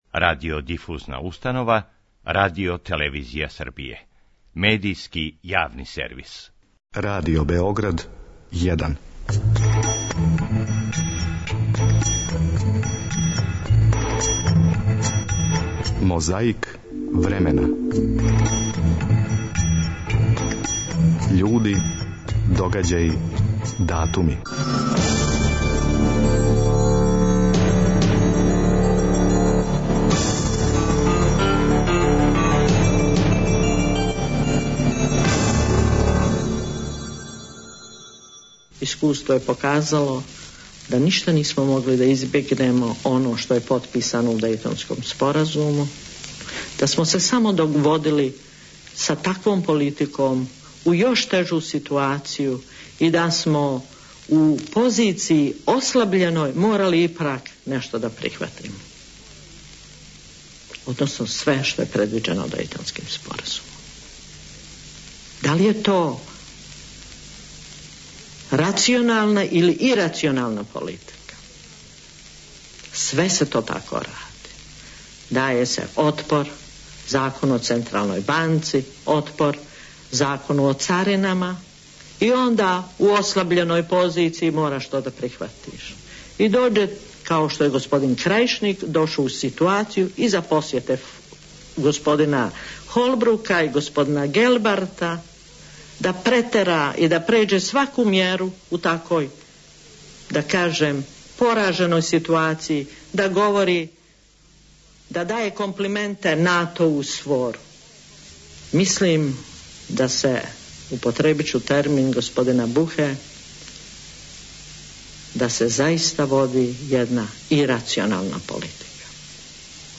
U ime reprezentativaca, govorio je kapiten Aleksandar Saša Đorđević.
Podsećamo vas na govor Biljane Plavšić, 11. avgusta 1997. godine, na televiziji Banja Luka.
Подсећа на прошлост (културну, историјску, политичку, спортску и сваку другу) уз помоћ материјала из Тонског архива, Документације и библиотеке Радио Београда.